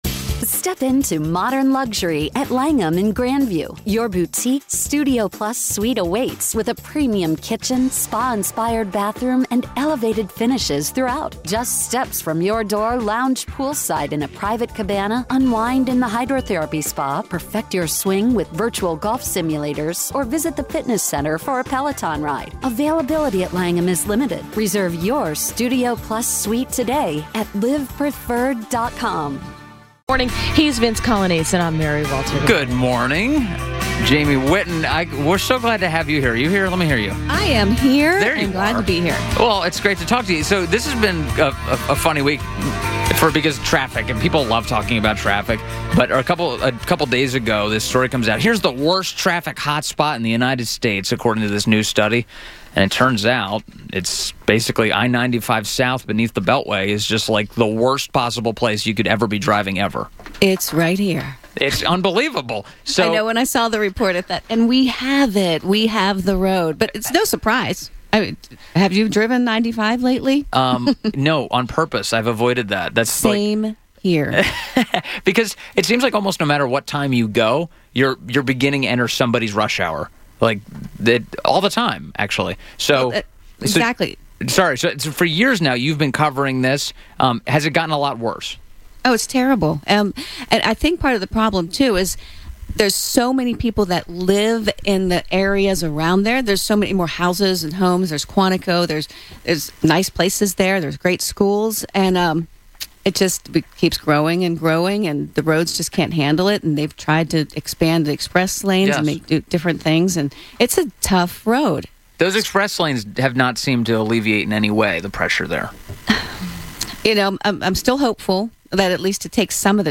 Facebook Twitter Headliner Embed Embed Code See more options Interview